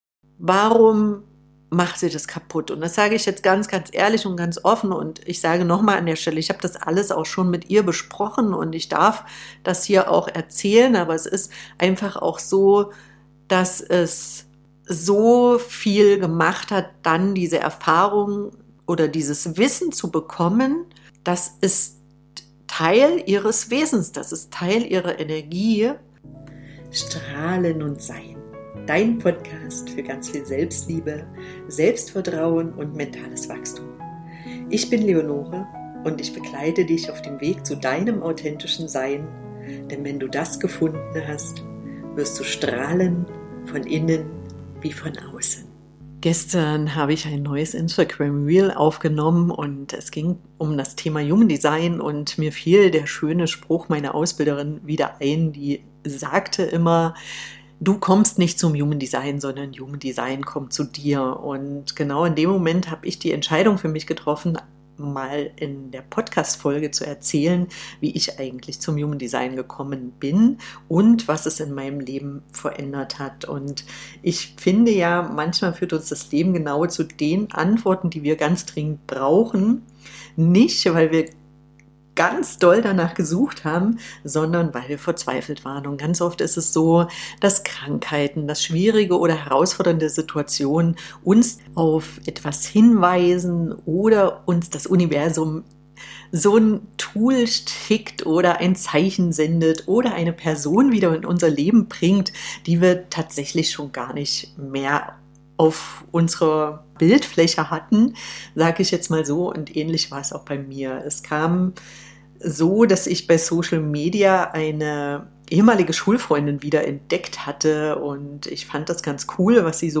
In dieser sehr persönlichen Solo-Folge nehme ich dich mit auf meine Anfänge mit Human Design und zeige dir, wie tiefgreifend dieses Wissen meine Sicht auf mich selbst, meine Mutterschaft und vor allem die Beziehung zu meiner Tochter verändert hat.